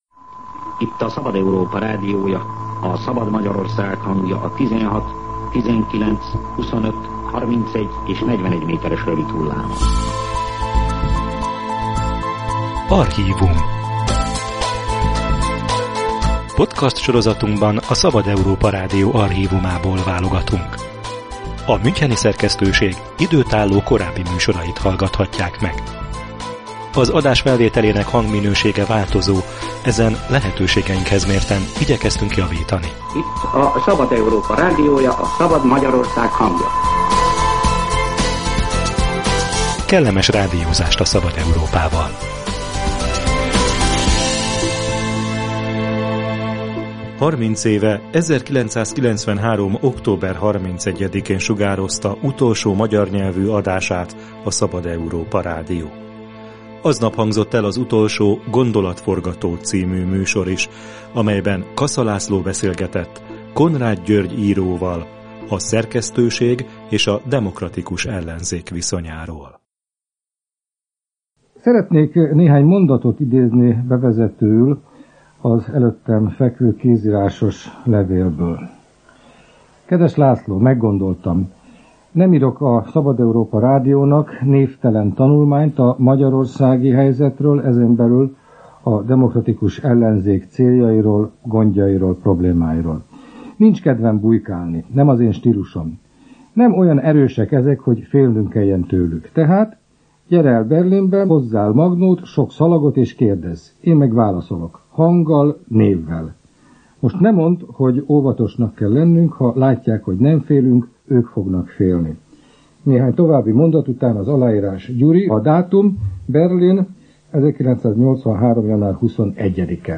Egy fenyegető újságcikk után is vállalta, hogy névvel nyilatkozzon a Szabad Európa Rádiónak 1983-ban az akkor Berlinben élő Konrád György író. Az 1993. október 31-i, utolsó adásnapon visszatekintettek erre az interjúra, valamint a rádió és a demokratikus ellenzék viszonyára.